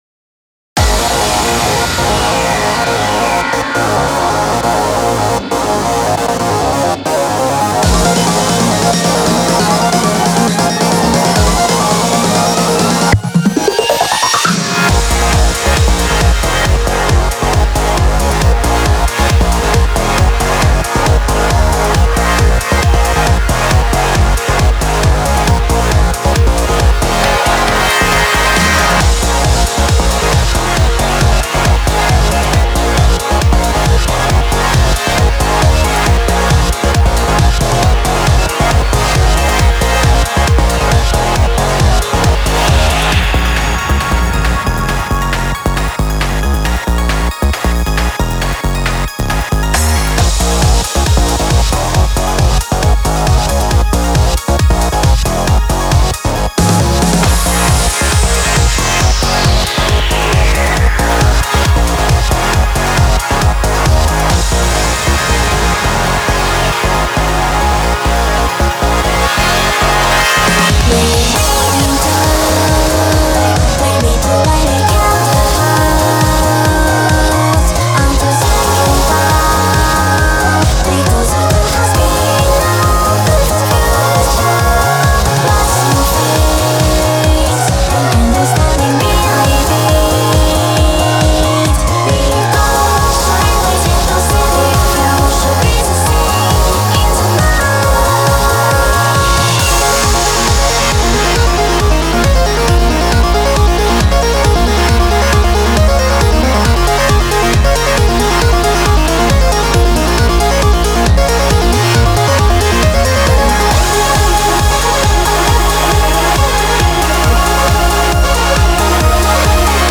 仮歌詞、仮歌のため、一部お見苦しい箇所があるかも知れませんが、